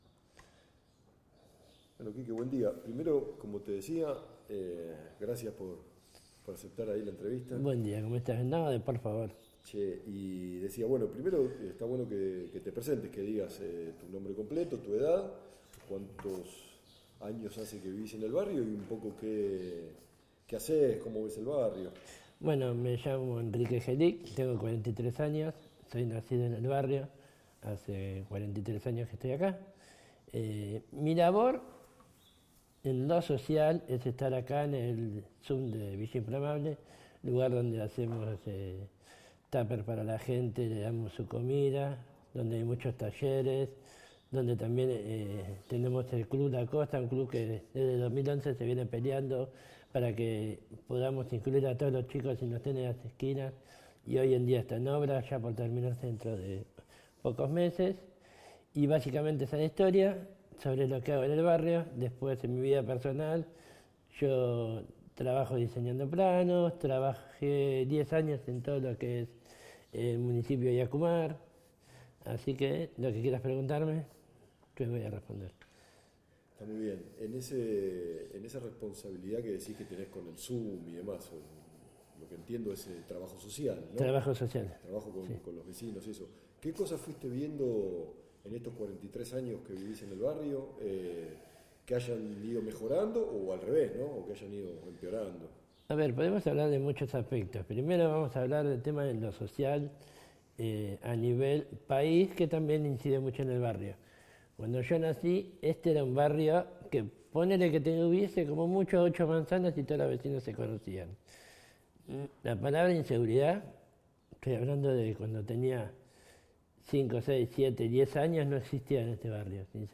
1 entrevista oral en soporte magnético